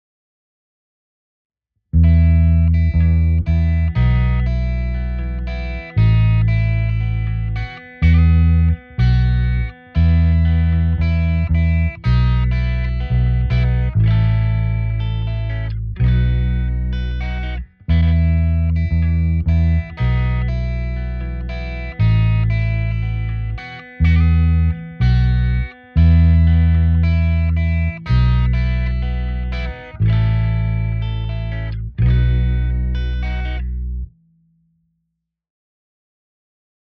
I isolated the analogs in the UA ASIO's render.
Much more bass.